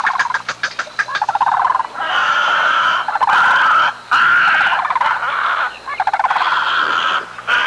Aviary Sound Effect
aviary.mp3